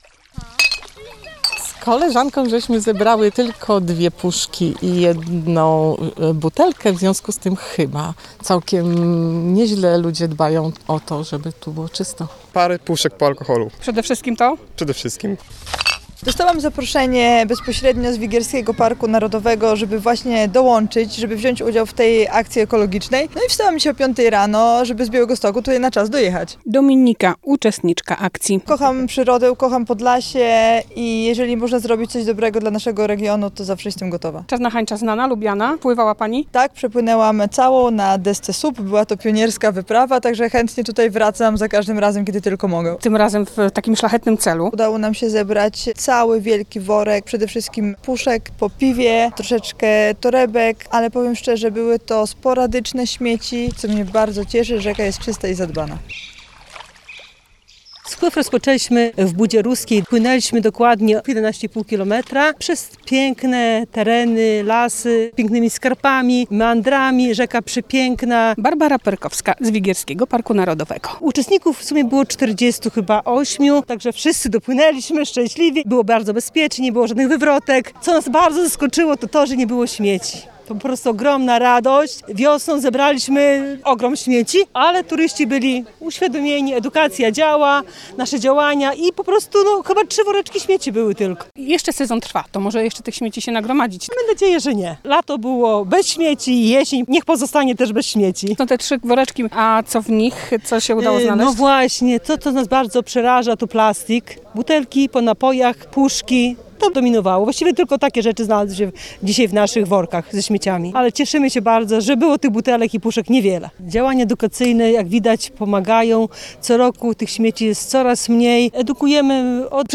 Sprzątanie Czarnej Hańczy - relacja